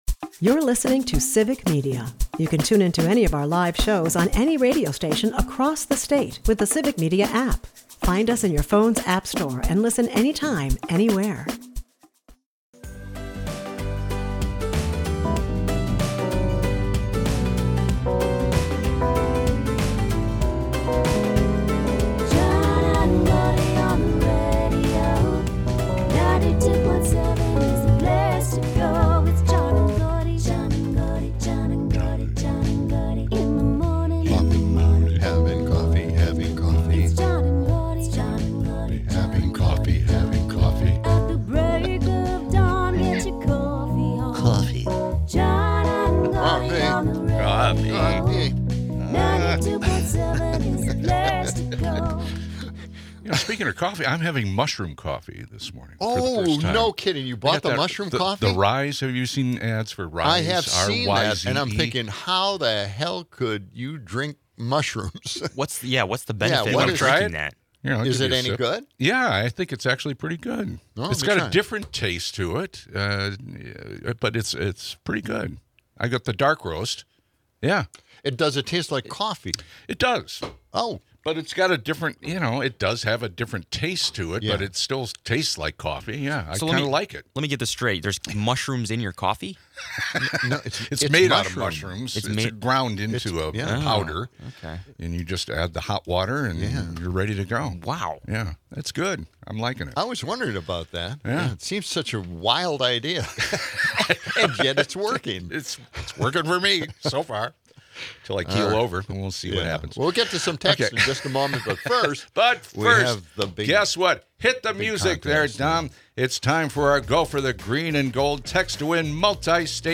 The hosts start with a caffeinated chat about mushroom coffee before diving into a heated discussion on Trump's controversial comments about using the military in U.S. cities as training grounds. They explore the chilling implications of the National Security Presidential Memorandum 7 and the potential erosion of civil liberties. The episode also touches on the ongoing government shutdown, its impact on healthcare, and the political maneuverings behind it.